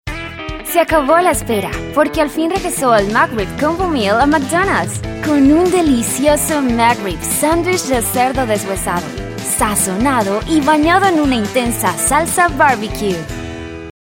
Voz versátil y expresiva, hablante nativa de español.
Tono neutro latinoamericano y acentos colombianos.
Tono: Medio (Natural) y Alto-Bajo Opcional.
Acentos: Español colombiano nativo y español neutro LATAM.